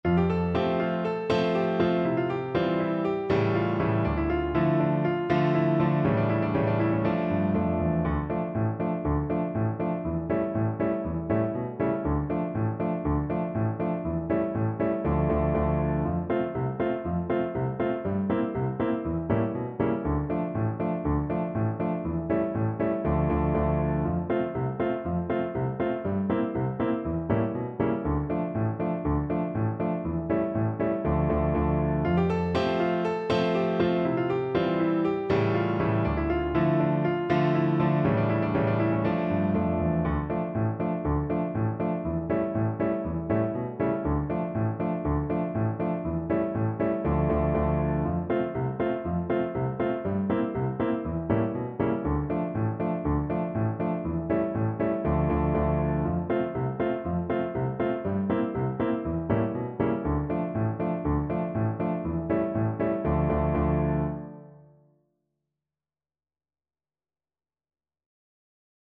Free Sheet music for Flute
Flute
4/4 (View more 4/4 Music)
D minor (Sounding Pitch) (View more D minor Music for Flute )
Allegro (View more music marked Allegro)
Traditional (View more Traditional Flute Music)
world (View more world Flute Music)
Ukrainian